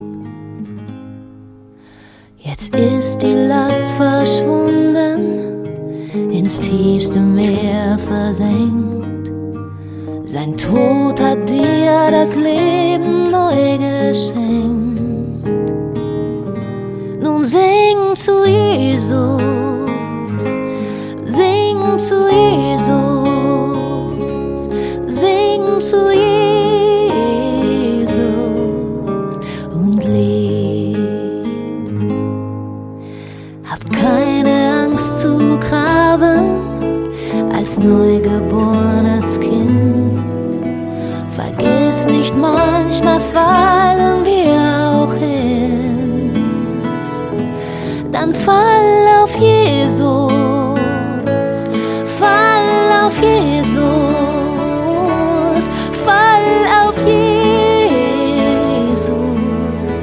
Neuere Gemeindelieder, Worship 0,99 €